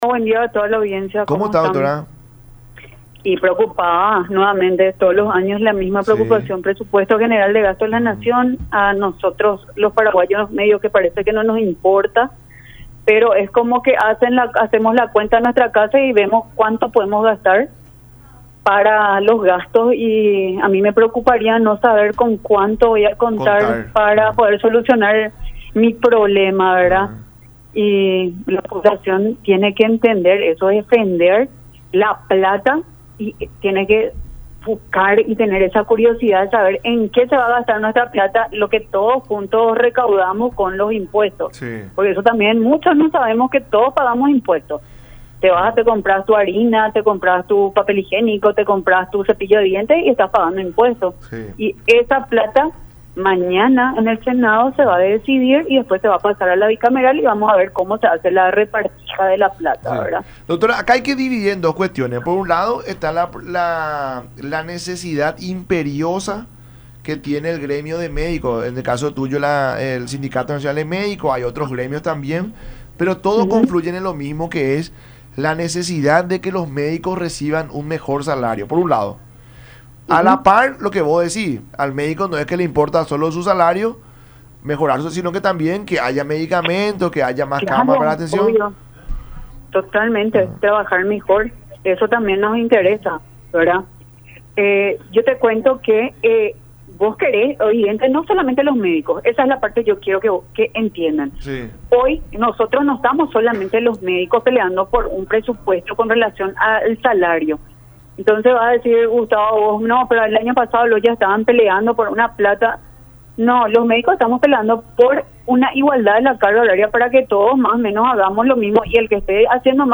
en conversación con La Mañana De Unión por Unión TV y radio La Unión.